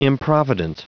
Prononciation du mot improvident en anglais (fichier audio)
Prononciation du mot : improvident